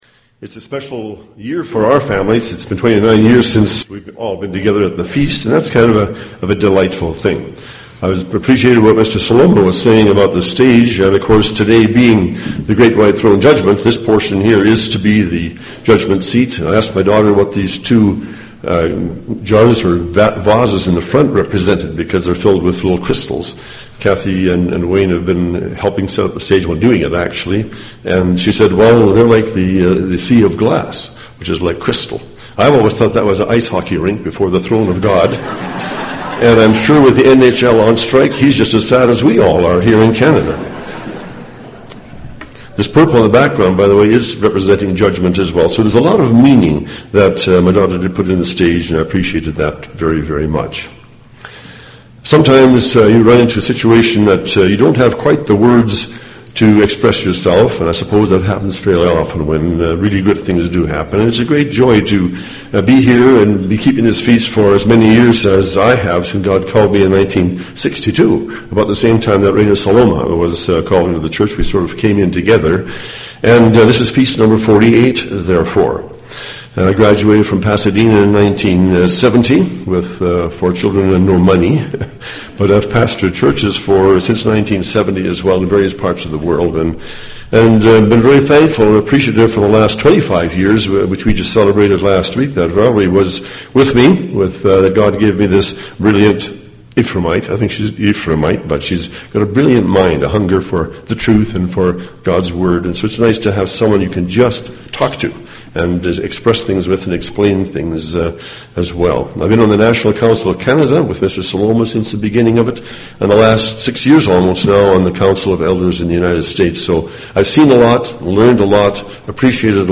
This sermon was given at the Canmore, Alberta 2012 Feast site.